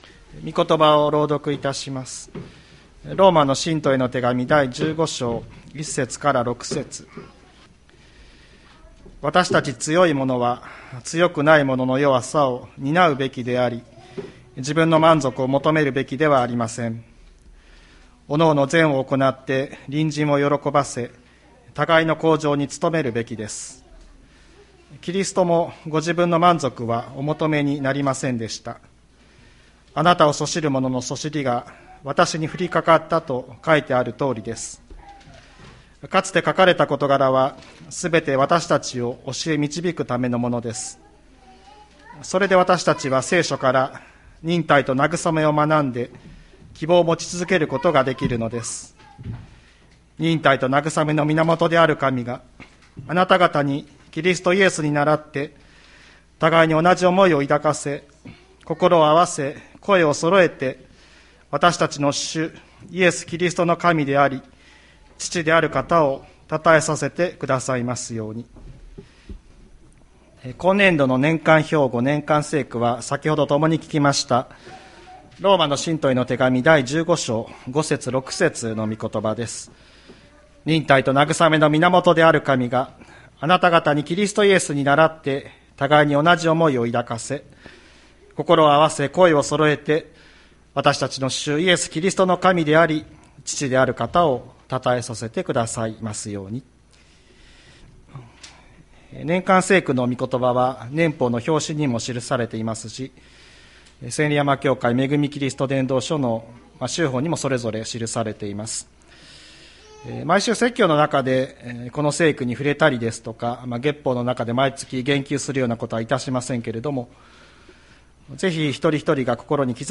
2023年01月29日朝の礼拝「忍耐と慰めの神を知る」吹田市千里山のキリスト教会
千里山教会 2023年01月29日の礼拝メッセージ。